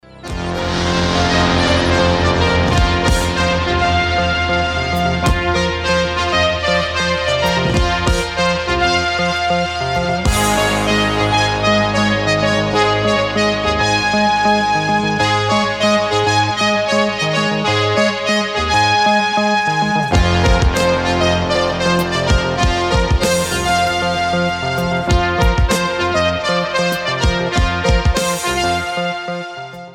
facevano uso massiccio di elettronica e sintetizzatori